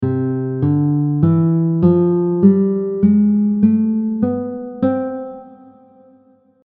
Major 6 diminished scale from root to octave
Barry-Harris-Major-6th-diminished-scale-.mp3